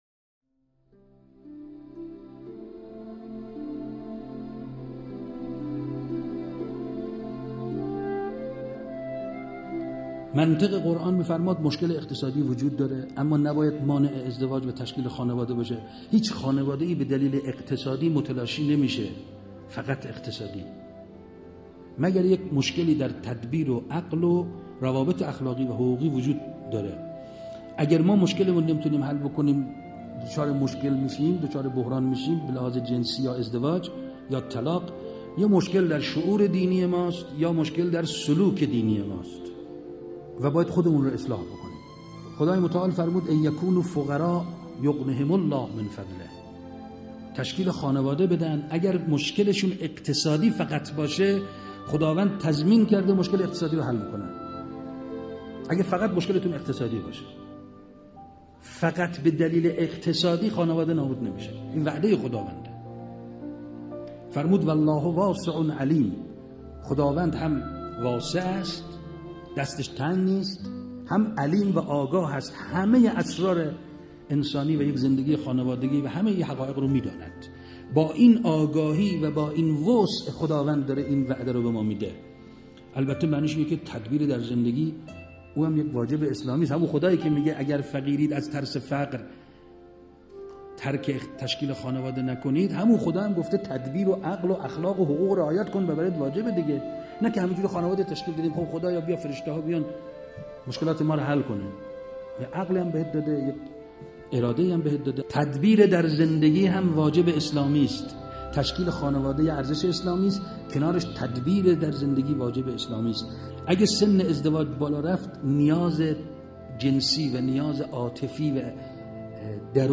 فرازی از سخنان استاد رحیم پور ازغدی